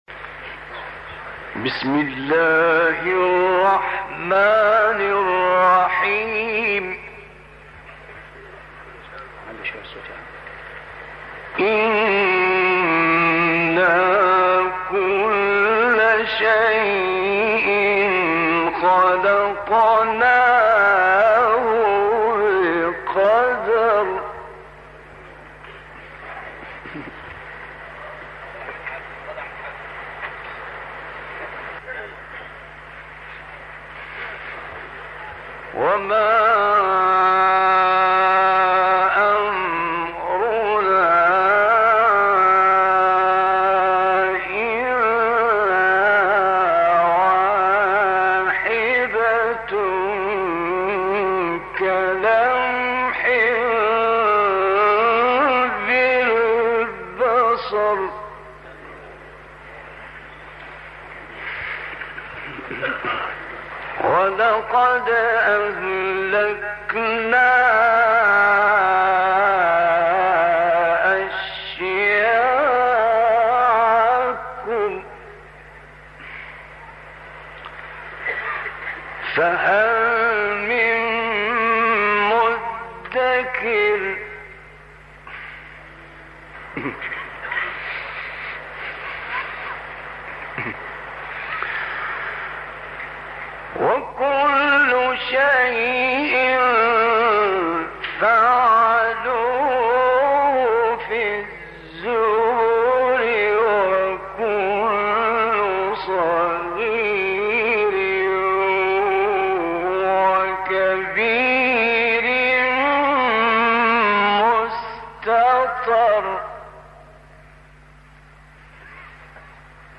تلاوت سوره های قمر و الرحمن با صدای استاد راغب مصطفی غلوش
تلاوت قرآن کريم
تلاوت قاری مصری